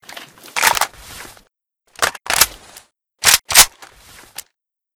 aks74u_reload_empty.ogg